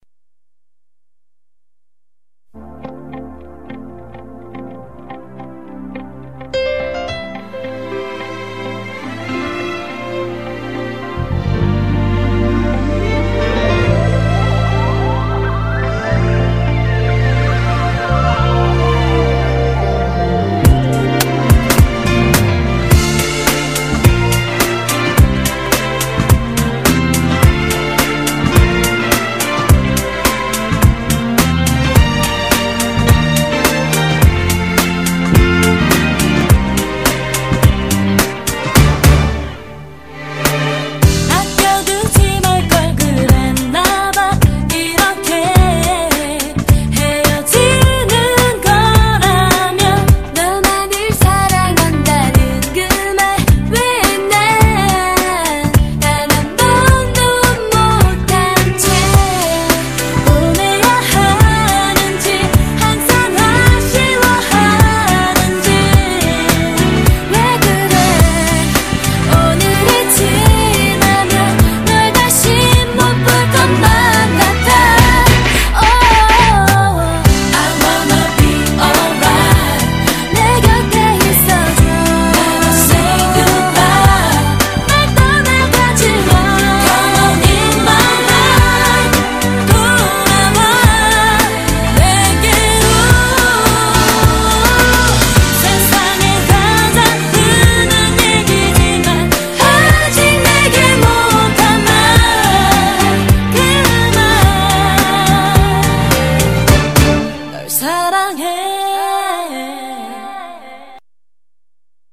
BPM105--1
Audio QualityPerfect (High Quality)